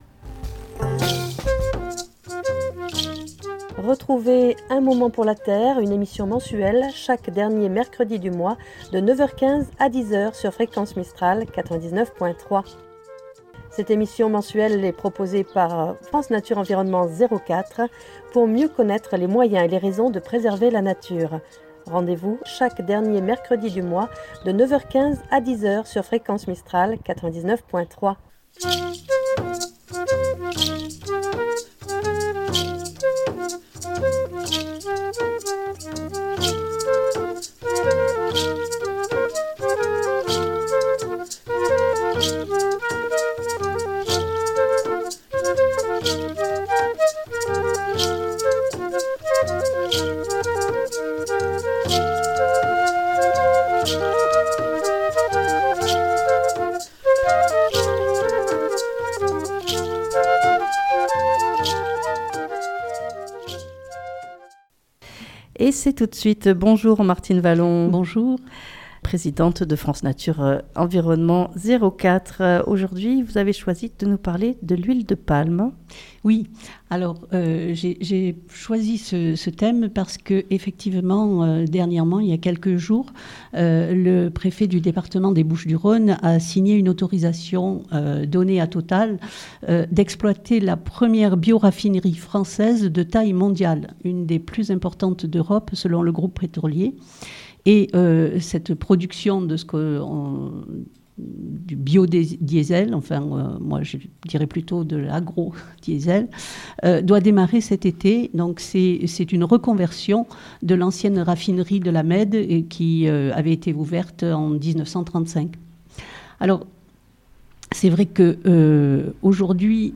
L'huile de Palme : danger L'exploitation de l'huile de palme et ses dangers sur le plan social, environnemental, et sur la santé. C'est le thème de cette émission Un moment pour la terre.